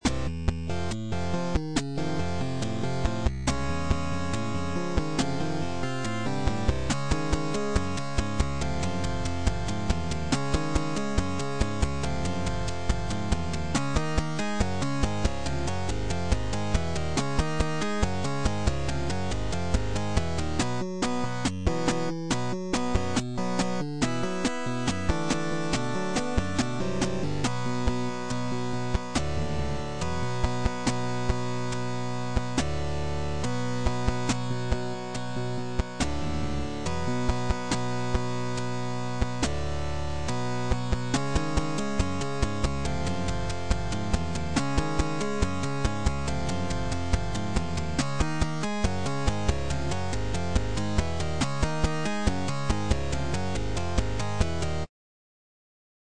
There is an occasional sour bass note, but otherwise I’m happy with the progress so far.
The melodies, parts, and arrangements were all formed procedurally.